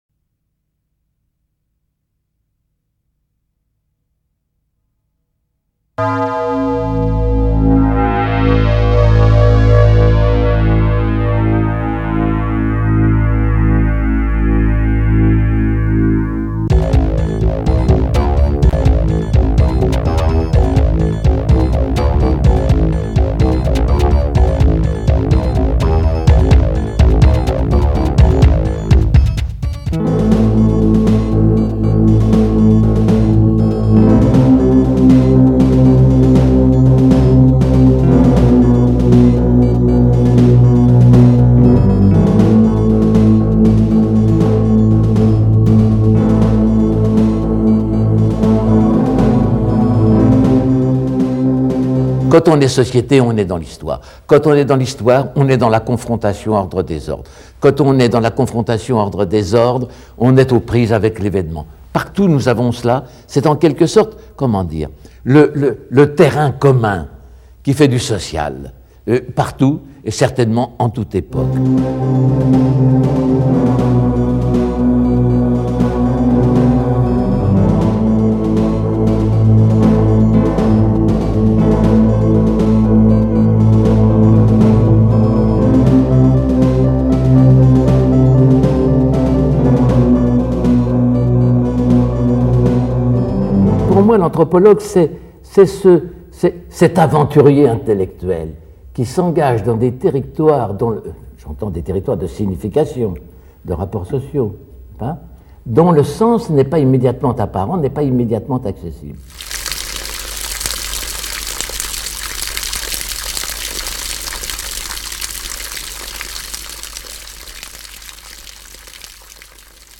Une anthropologie des moments critiques : Entretien avec Georges Balandier | Canal U